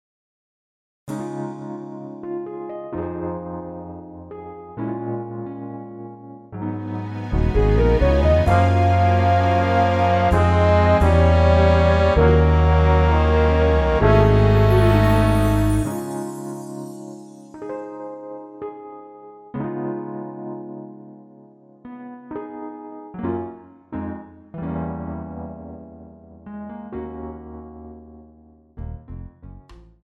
원키에서(-2)내린 MR 입니다.(미리듣기 참조)
Db
앞부분30초, 뒷부분30초씩 편집해서 올려 드리고 있습니다.